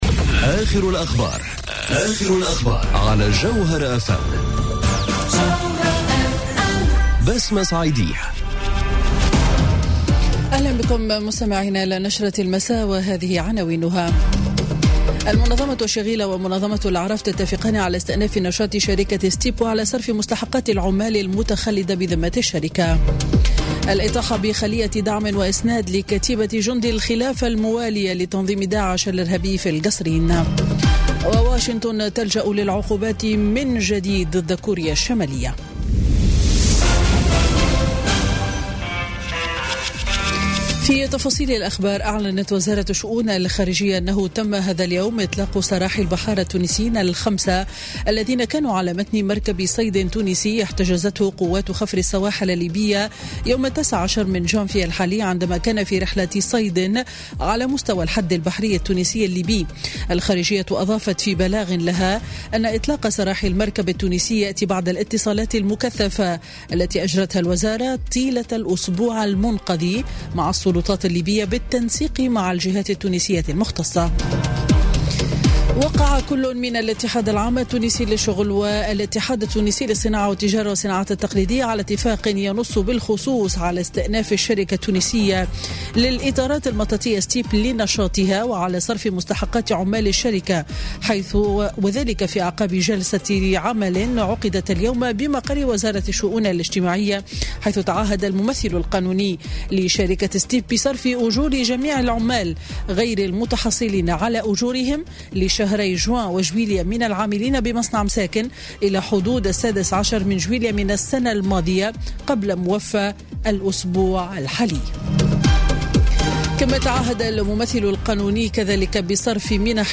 نشرة أخبار السابعة مساءً ليوم الأربعاء 24 جانفي 2018